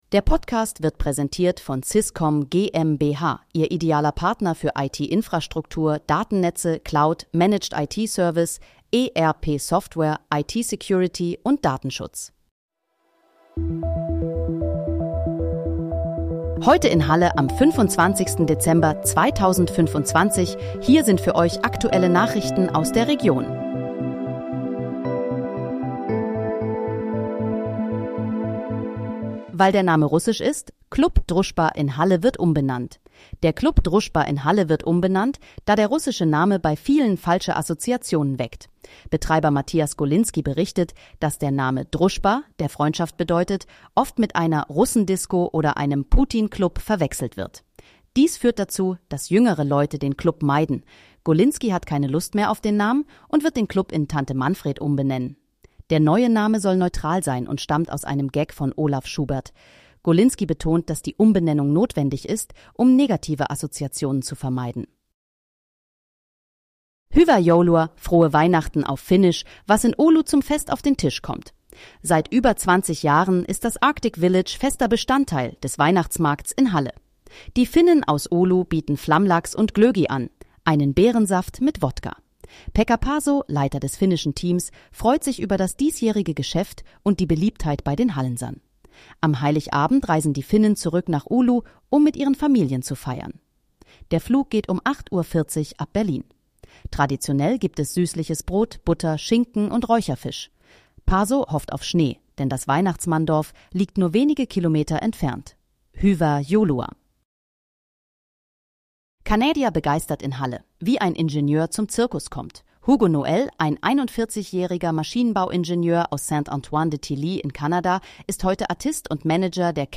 Heute in, Halle: Aktuelle Nachrichten vom 25.12.2025, erstellt mit KI-Unterstützung
Nachrichten